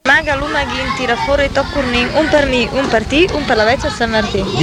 filastrocca - lumaga lumaghin (Ferrara).mp3